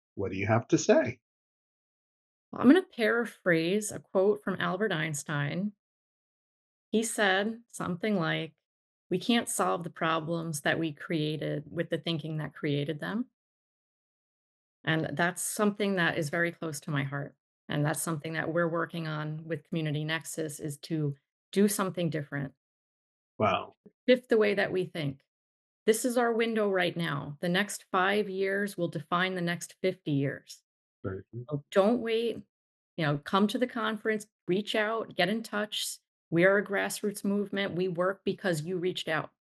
each episode features candid and compelling conversations with guests who have something meaningful to contribute to the dialogue.